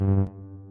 描述：这是一种模拟按键的音频效果
标签： 按键 按键 音响
声道立体声